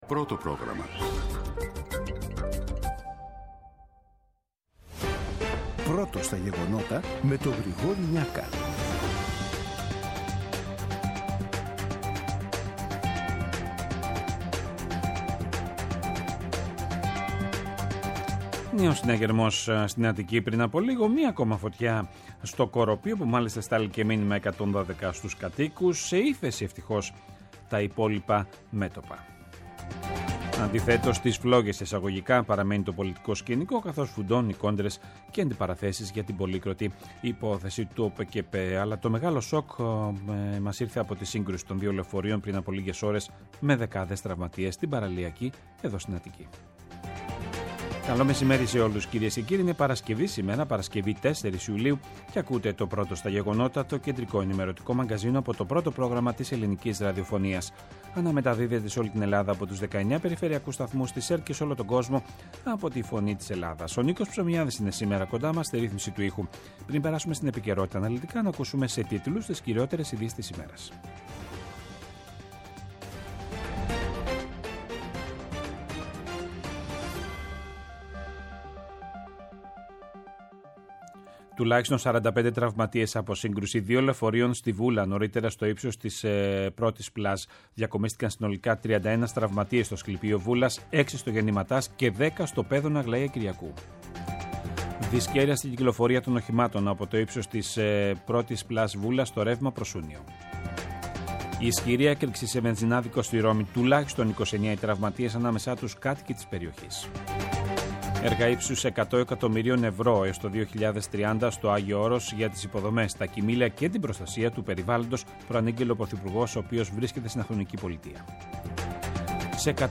Το αναλυτικό ενημερωτικό μαγκαζίνο του Α΄ Προγράμματος στις 14:00. Με το μεγαλύτερο δίκτυο ανταποκριτών σε όλη τη χώρα, αναλυτικά ρεπορτάζ και συνεντεύξεις επικαιρότητας.